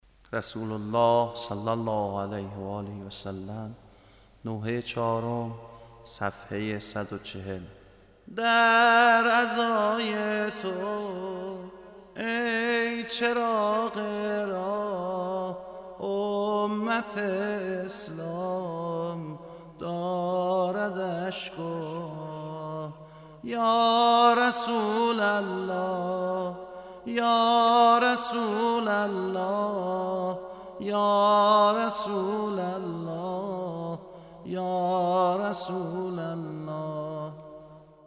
مصیبت شهادت رسول اکرم